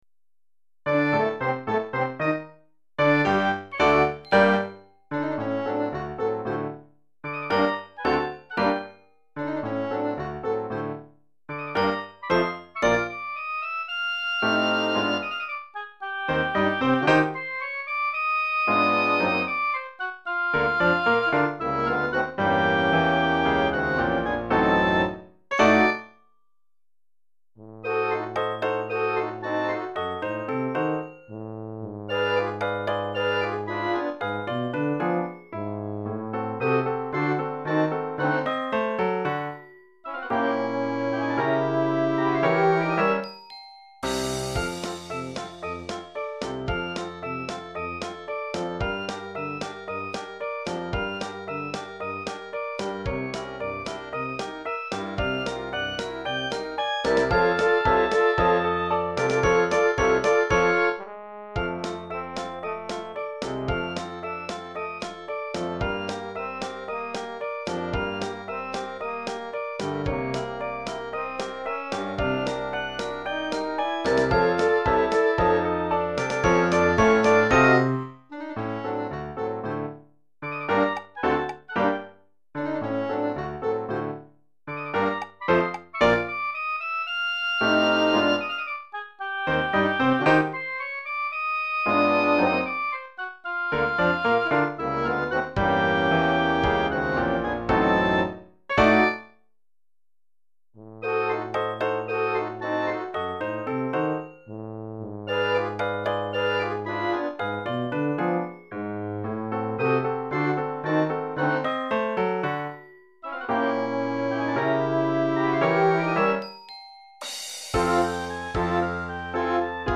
Orchestre d'Harmonie Junior et Piano